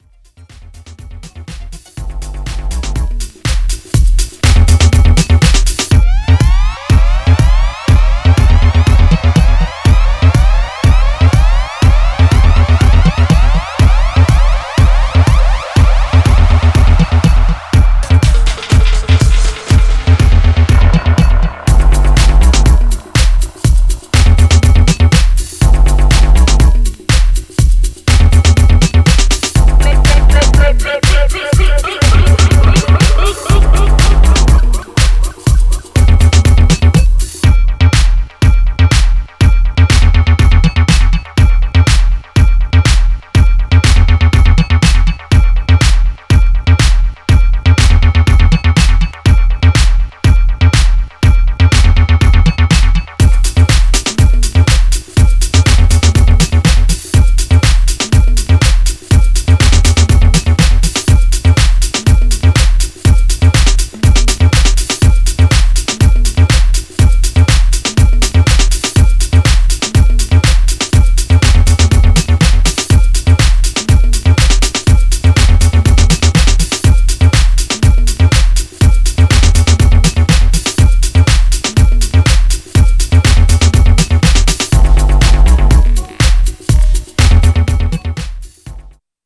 Styl: Electro, House, Techno, Breaks/Breakbeat